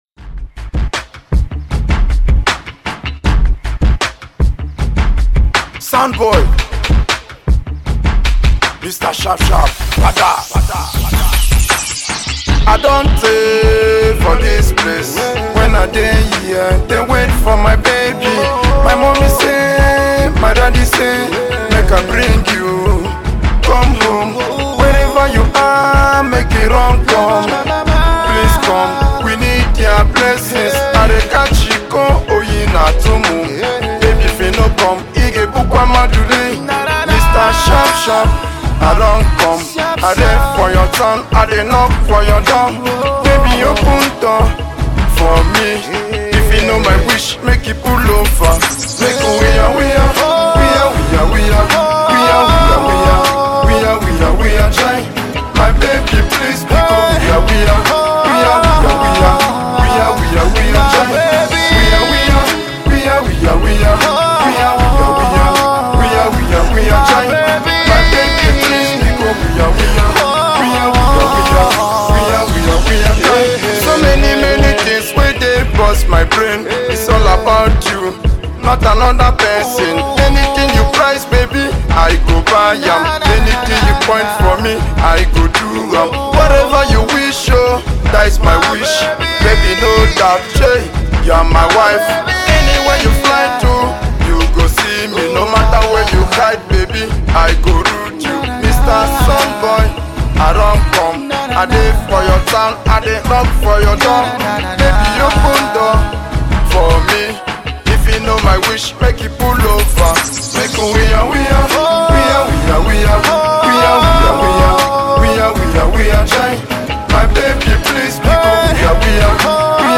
AudioPop